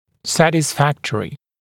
[ˌsætɪs’fæktərɪ][ˌсэтис’фэктэри]удовлетворительный